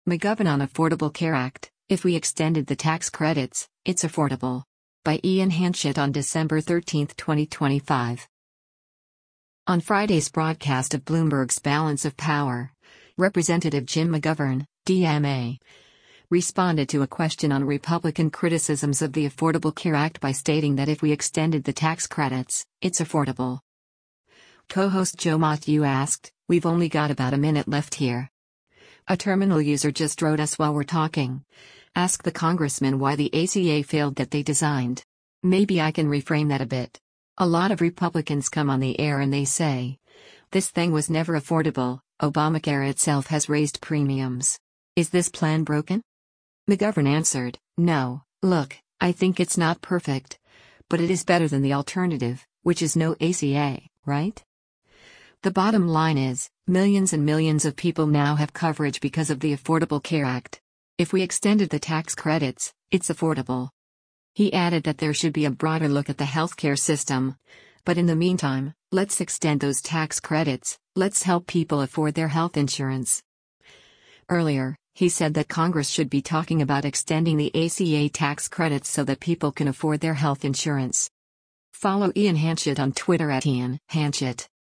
On Friday’s broadcast of Bloomberg’s “Balance of Power,” Rep. Jim McGovern (D-MA) responded to a question on Republican criticisms of the Affordable Care Act by stating that “If we extended the tax credits, it’s affordable.”